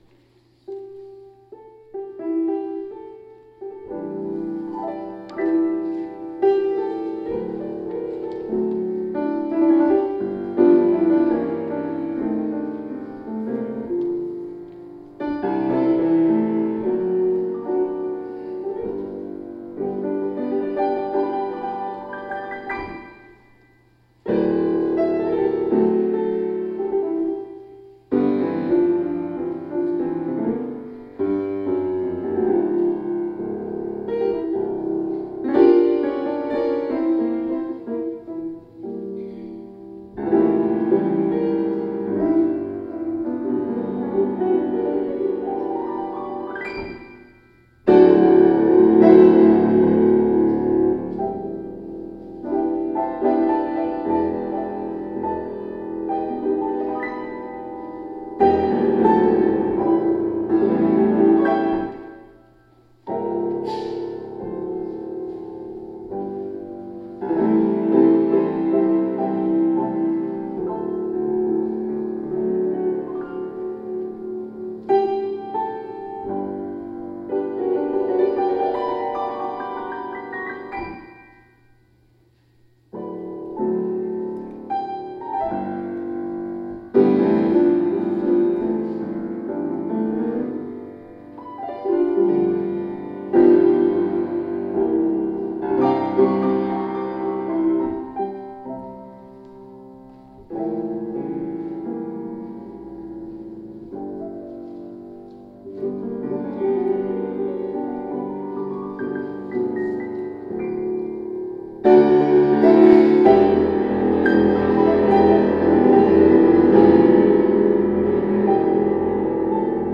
Shine On Me -  Traditional Gospel Blues